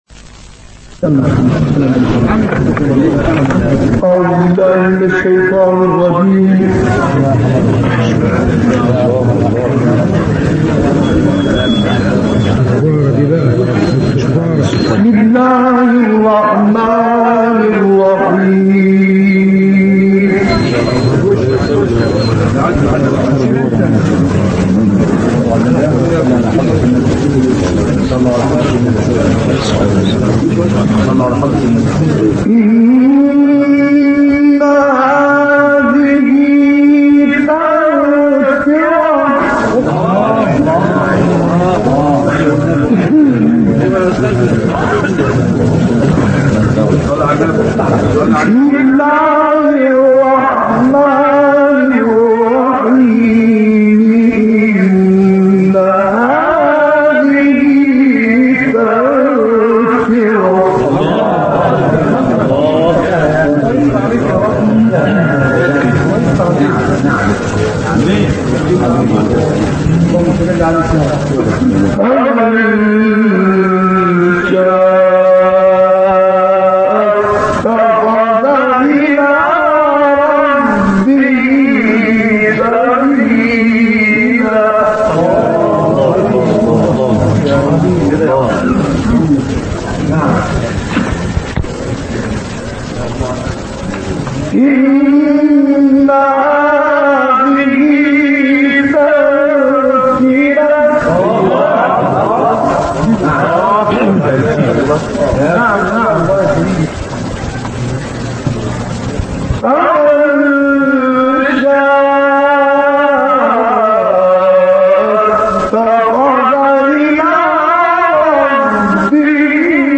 Une combinaison des styles de récitation anciens et modernes